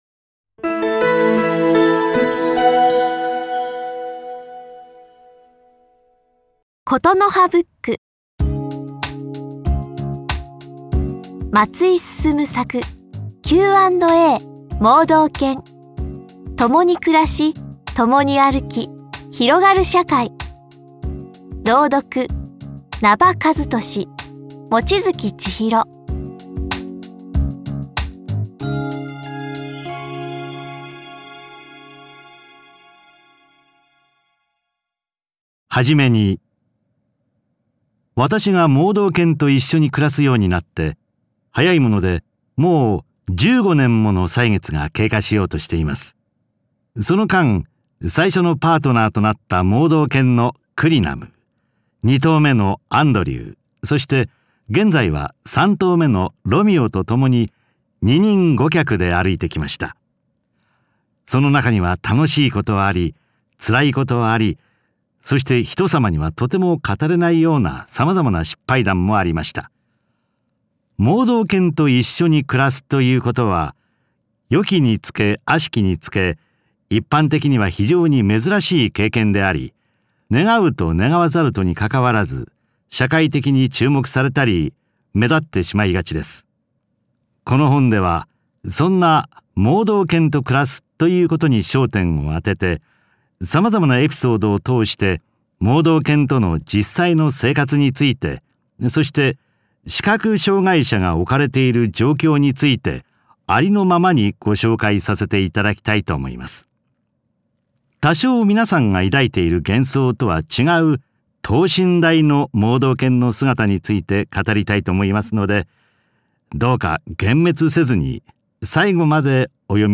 [オーディオブック] Q&A盲導犬 ともに暮らし、ともに歩き、広がる社会
非常に聴きやすく読み上げていただきました。